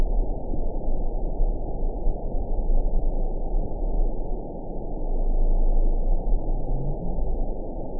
event 922679 date 03/09/25 time 20:22:54 GMT (3 months, 1 week ago) score 7.80 location TSS-AB10 detected by nrw target species NRW annotations +NRW Spectrogram: Frequency (kHz) vs. Time (s) audio not available .wav